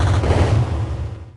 Techmino/media/effect/chiptune/clear_6.ogg at 89134d4f076855d852182c1bc1f6da5e53f075a4
chiptune